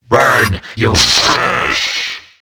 Android Infantry (Sound Voice)
The compilation contains 17 sounds, and here are samples of the android's best dialogue.
android_attack5_129.wav